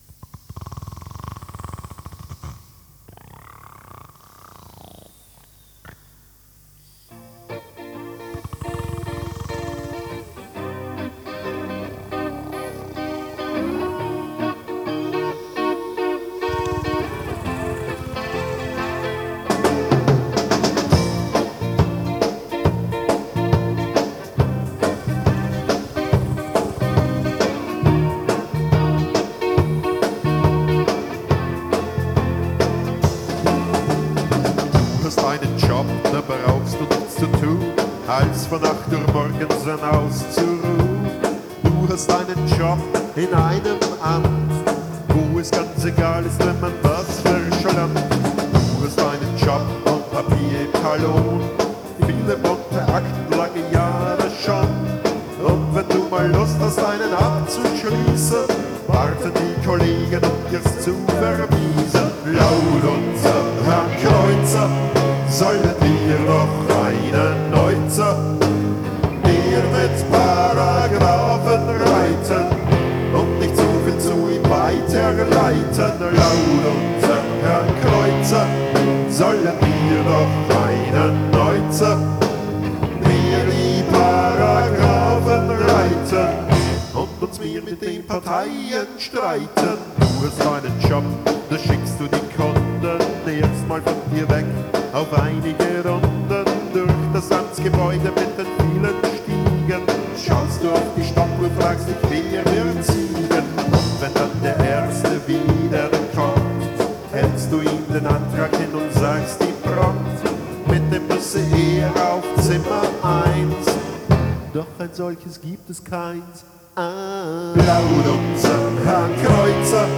Genre:   Freie Musik - Austro-Pop